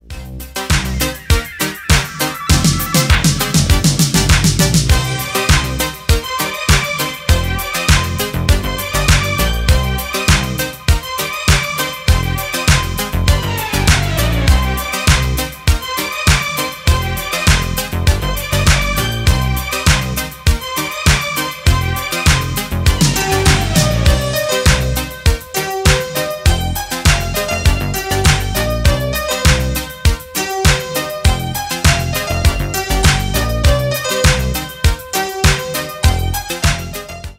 80-ые
инструментал